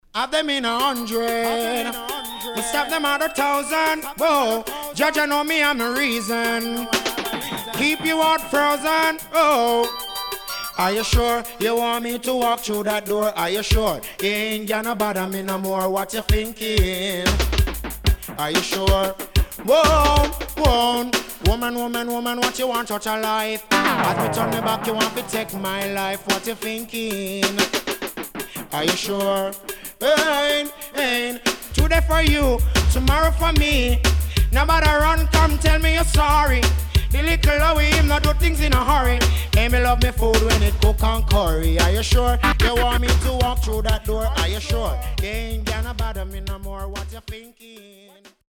HOME > Back Order [DANCEHALL DISCO45]
W-Side Good Dancehall.Good Condition